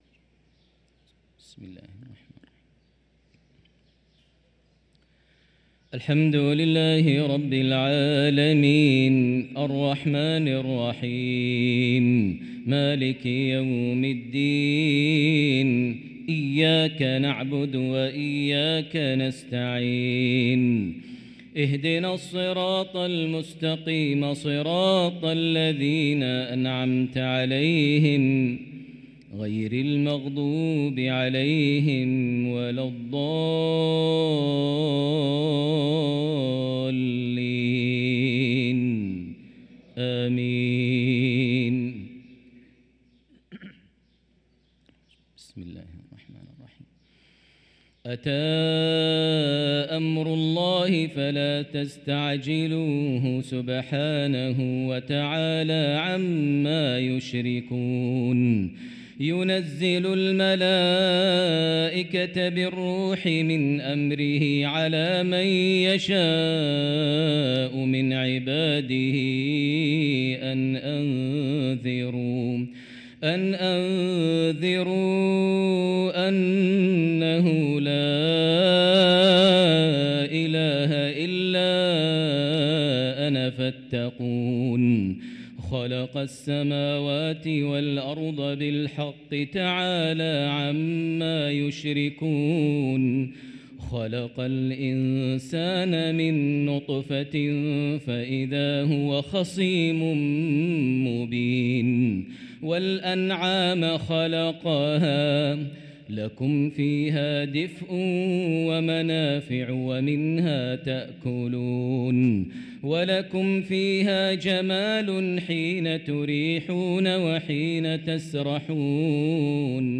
صلاة العشاء للقارئ ماهر المعيقلي 27 جمادي الأول 1445 هـ